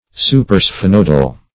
Search Result for " supersphenoidal" : The Collaborative International Dictionary of English v.0.48: Supersphenoidal \Su`per*sphe*noid"al\, a. (Anat.) Situated above, or on the dorsal side of, the body of the sphenoid bone.